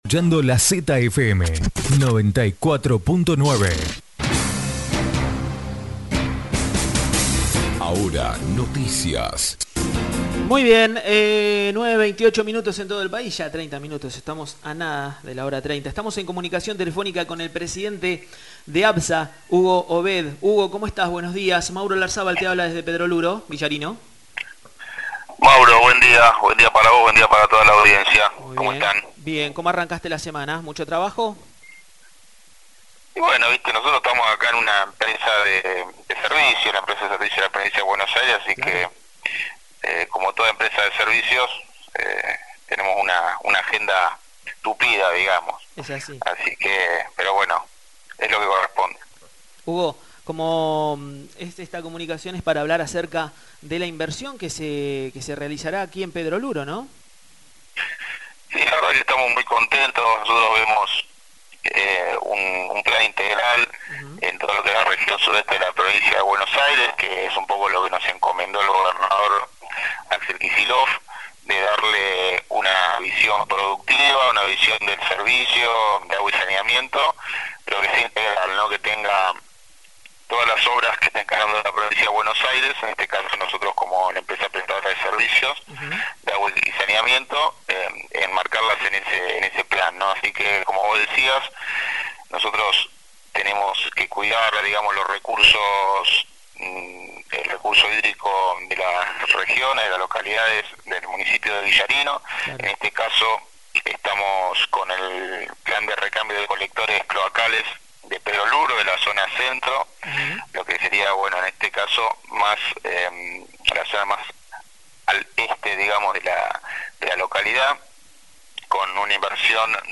En comunicación Telefónica con el Presidente de Aguas Bonaerenses,(ABSA), Hugo Obed, anunció la inversión de Aproximadamente $500 millones de pesos en el recambio de colectores cloacales en la localidad. Los tramos a intervenir van desde los 70 metros hasta los 240 metros , se realizarán 8 intervenciones que se concentrarán en la zona céntrica y casco urbano.